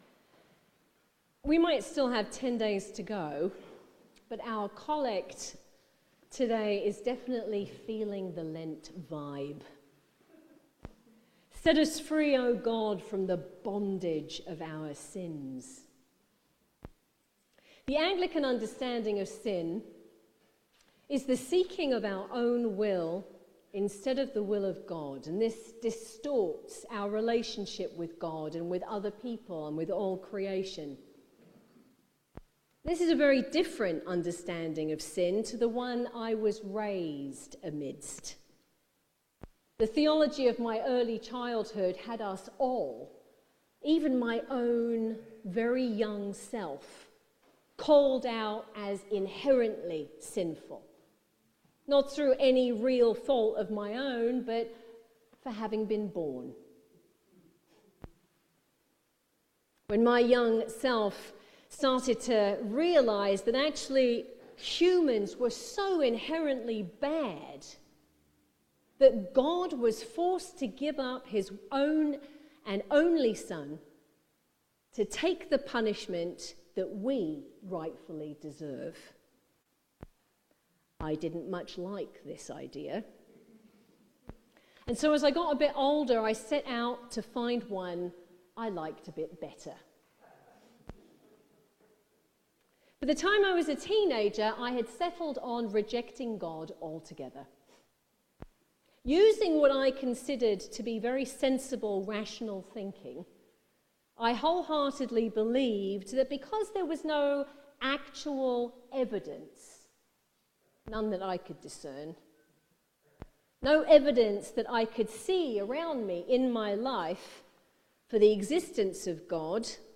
Feb-8th-Sermon.mp3